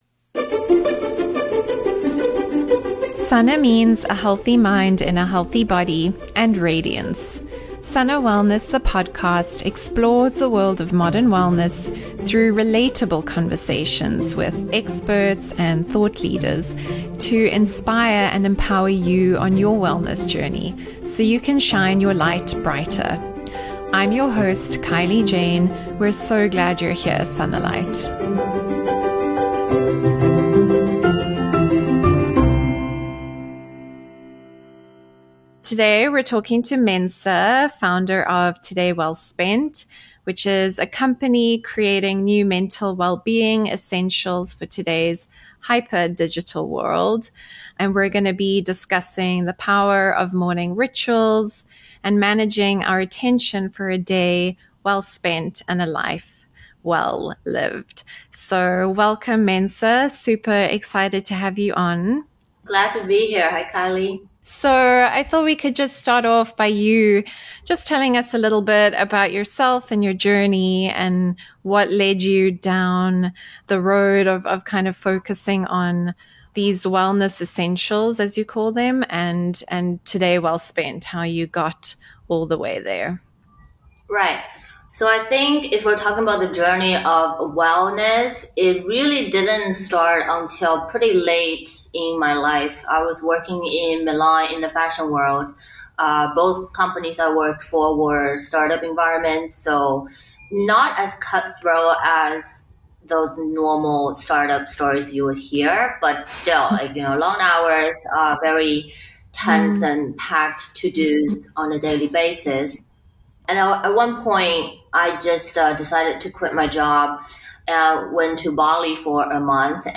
Welcome to SANA Wellness – your source for nourishing, effortless, everyday wellness where we explore the world of modern wellness through inspiring and relatable conversations with experts and thought leaders to educate and empower you on your own health and wellness journey.